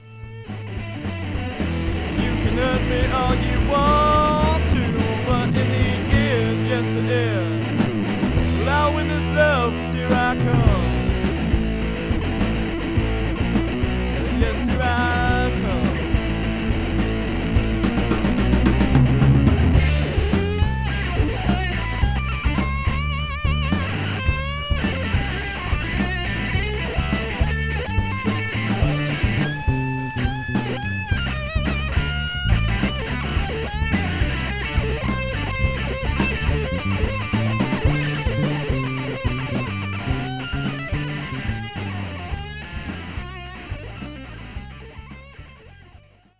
at their live performances